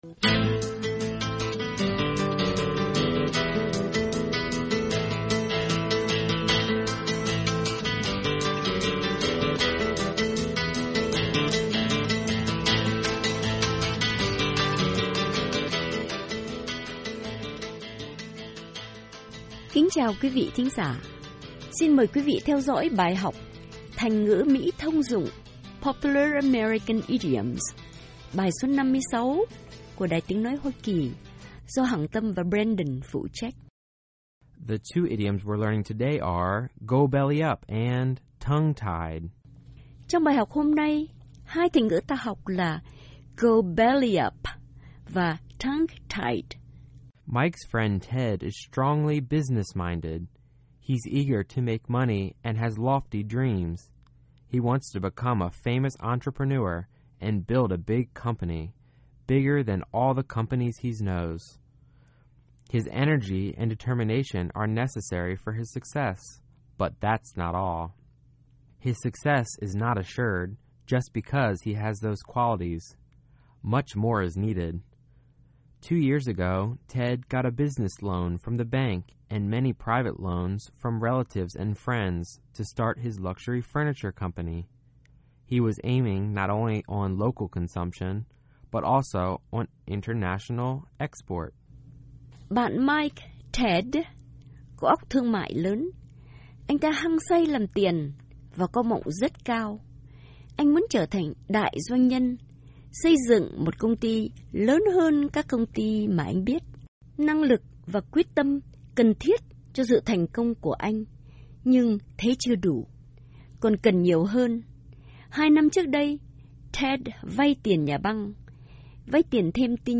Thành ngữ Mỹ thông dụng: Go belly-up / Tongue-tied